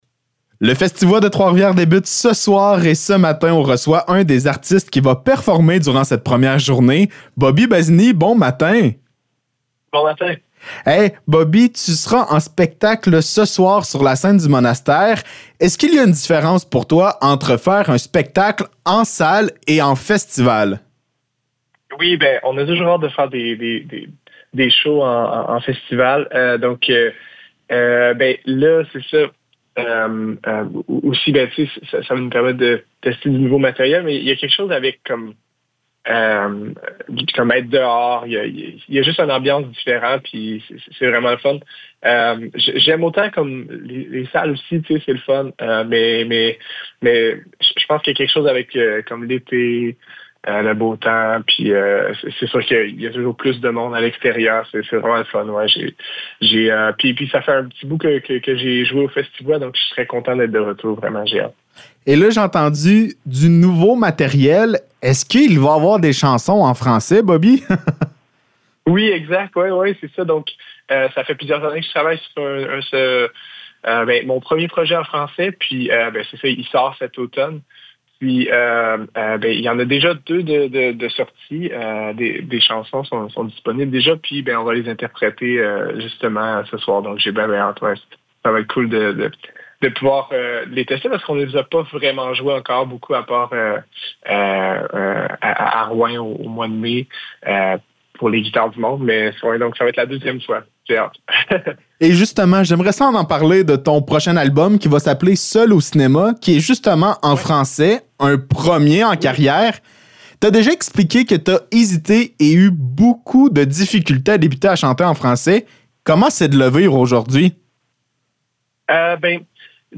Entrevue avec Bobby Bazini
ENTREVUE-BOBBY-BAZINI.mp3